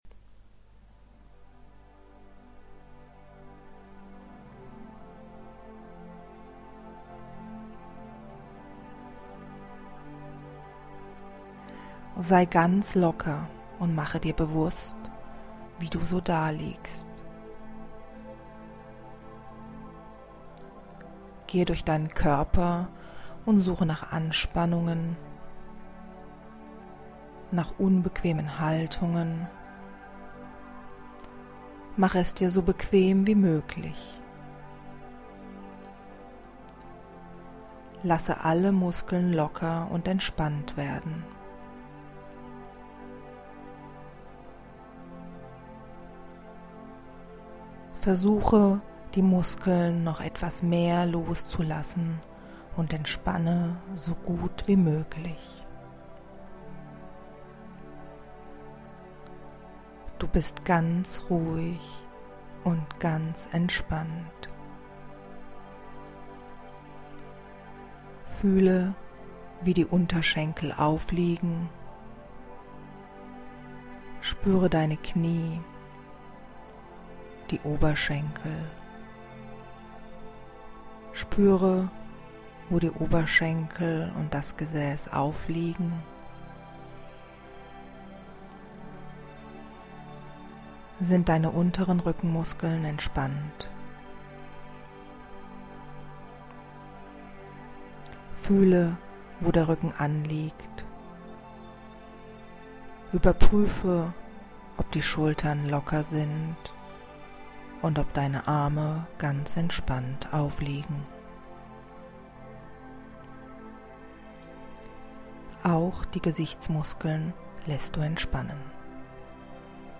MP3 Download Atemübung Autogenes Training
Autogenes-Training-Atemuebung.mp3